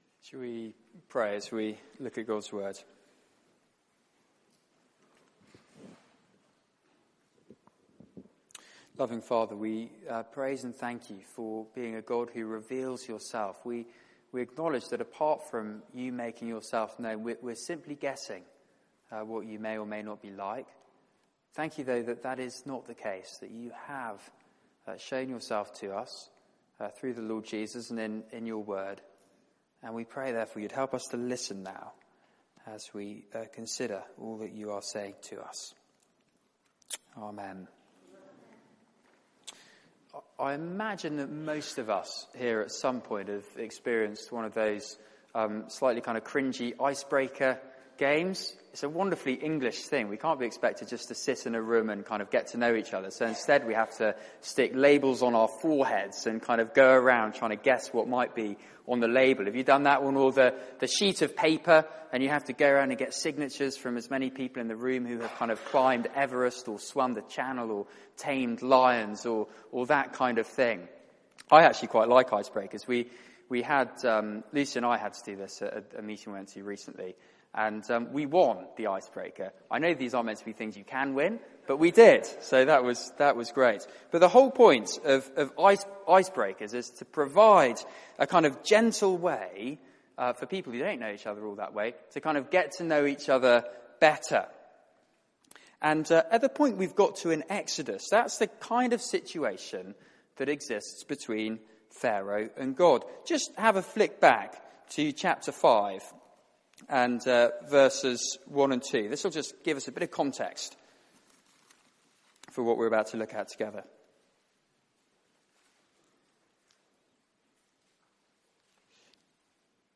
Media for 6:30pm Service on Sun 21st Jun 2015 18:30 Speaker
Series: From slavery to freedom Theme: The ten plagues Sermon